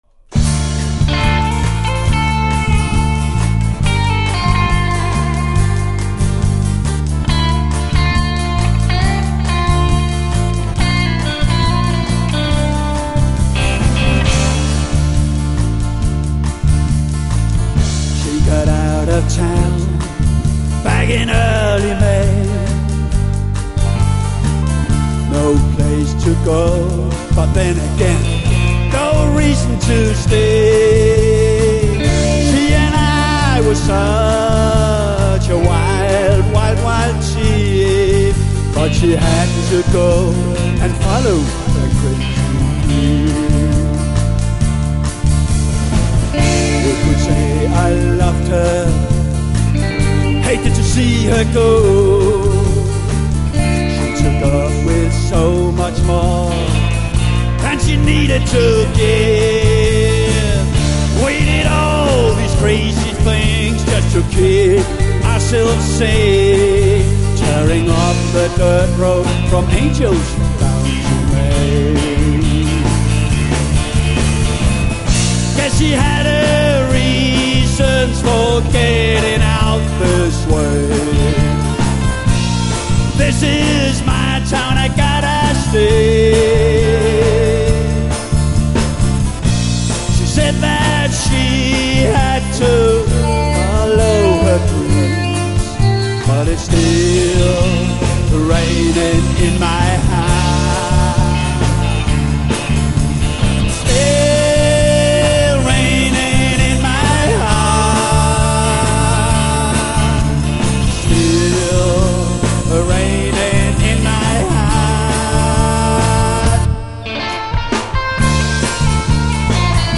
demostudie, K�benhavn